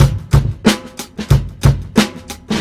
• 92 Bpm Breakbeat C# Key.wav
Free drum loop - kick tuned to the C# note. Loudest frequency: 1017Hz
92-bpm-breakbeat-c-sharp-key-jC0.wav